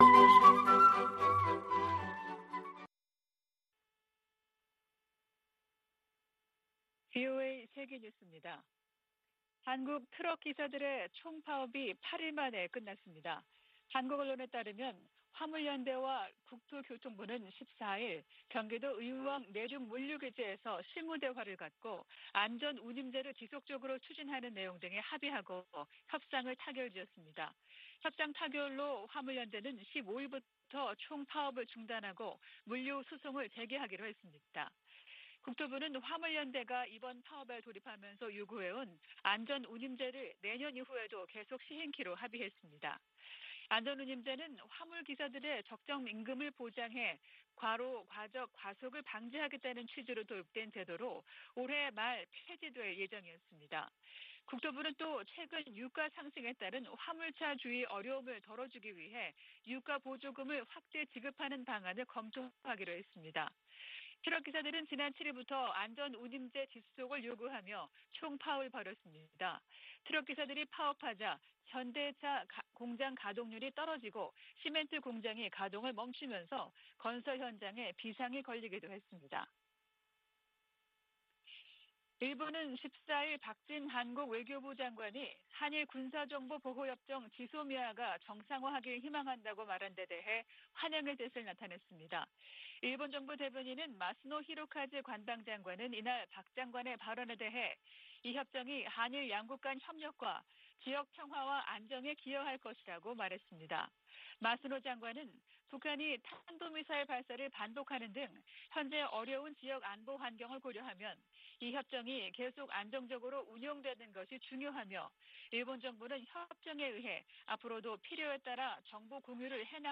VOA 한국어 아침 뉴스 프로그램 '워싱턴 뉴스 광장' 2022년 6월 15일 방송입니다. 토니 블링컨 미 국무장관은 북한 핵실험에 단호한 대응을 예고하면서, 대화 응하지 않으면 압박을 강화할 것이라고 경고했습니다. 북한이 '강대강 정면투쟁' 원칙을 내세움에 따라 미-한-일 세 나라는 안보 협력을 강화하는 양상입니다. 북한의 방사포 역량이 핵무기 탑재가 가능한 미사일급으로 증대됐다고 미국의 전문가들이 진단했습니다.